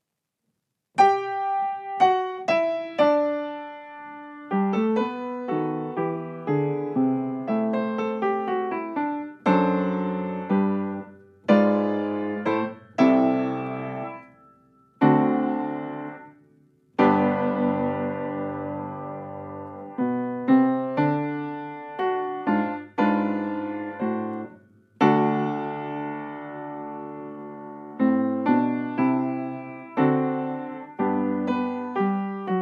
Allegro moderato: 120 BMP
Nagranie dokonane na pianinie Yamaha P2, strój 440Hz
piano